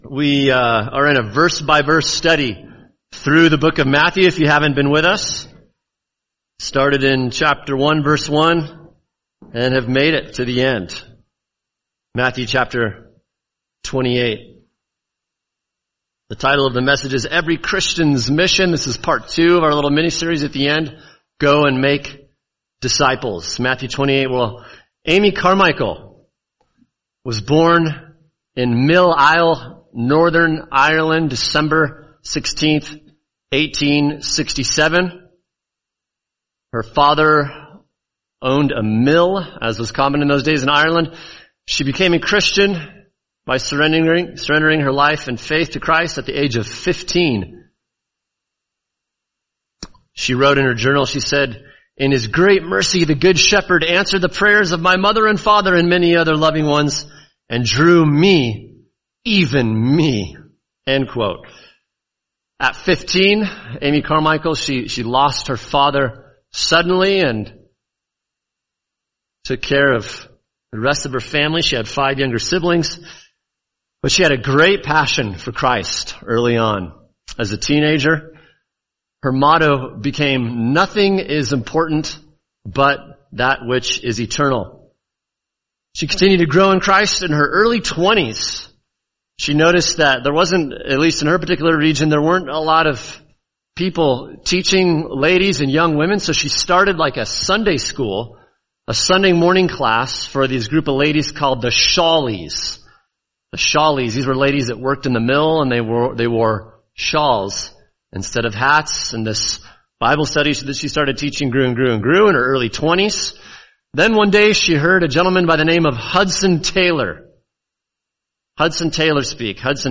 [sermon] Matthew 28:16-20 Every Christian’s Mission – Part 2: Go And Make Disciples | Cornerstone Church - Jackson Hole